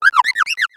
Cri de Victini dans Pokémon X et Y.